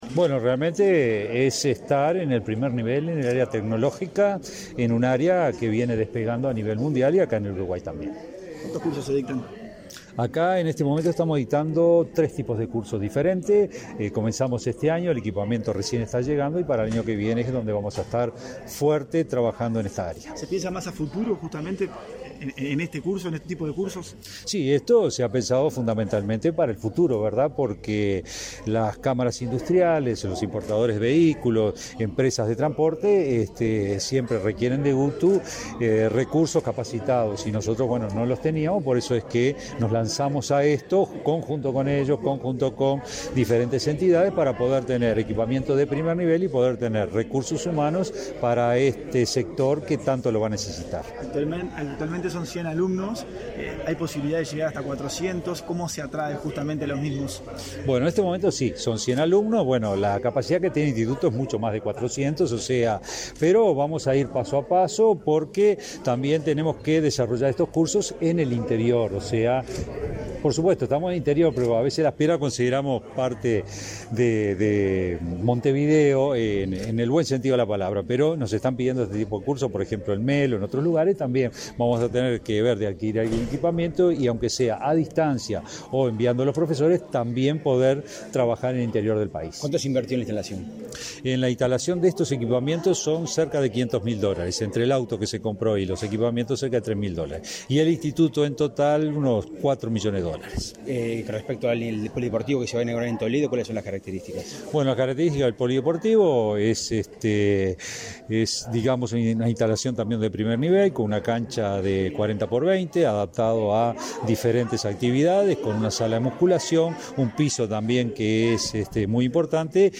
Declaraciones de prensa del director general de UTU, Juan Pereyra
Declaraciones de prensa del director general de UTU, Juan Pereyra 12/10/2023 Compartir Facebook X Copiar enlace WhatsApp LinkedIn Autoridades de la Administración Nacional de Educación Pública y de la UTU participaron, este 12 de octubre, en la inauguración del Instituto de Alta Especialización (IAE) en Electromovilidad, en la localidad de Las Piedras. Tras el evento, el director general de la UTU, Juan Pereyra, realizó declaraciones a la prensa.